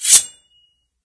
Sword5.ogg